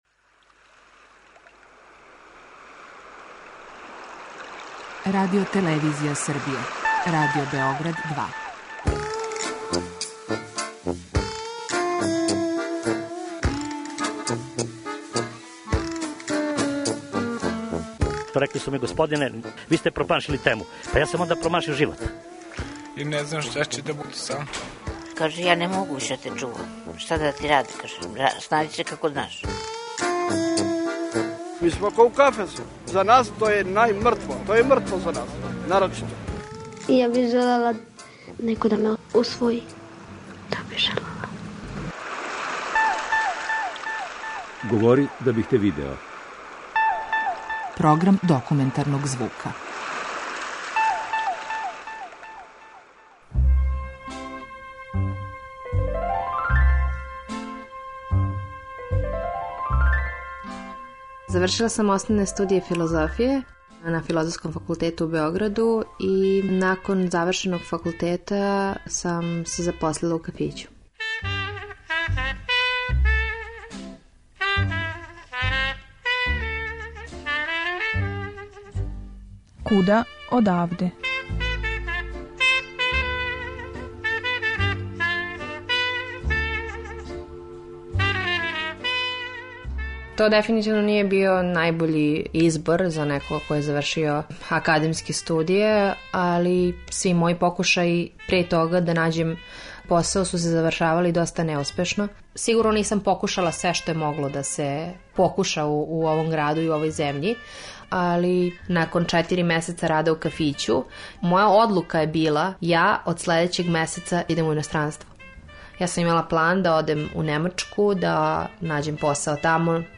Документарни програм
У репортажи "Куда одавде", о свом искуству рада у Абу Дабију, потрази за животном перспективом и изневереним очекивањима, говориће девојка која је завршила студије филозофије у Београду и запутила се у иностранство добивши пословну прилику која је обећавала велику плату и наизглед достојанствене услове рада.